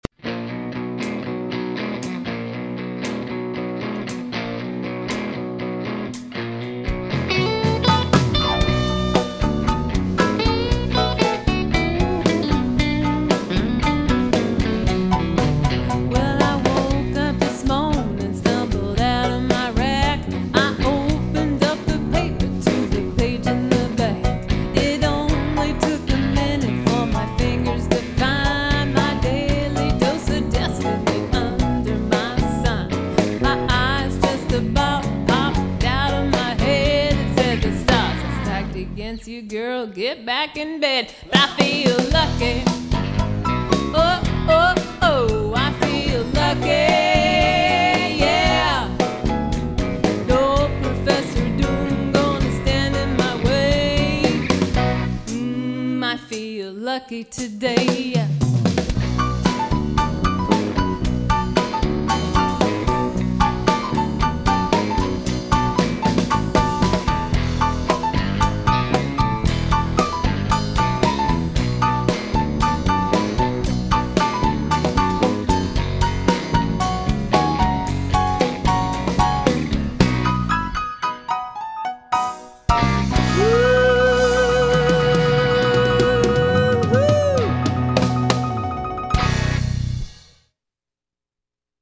Recorded at Polar Productions Studio, Pismo Beach, CA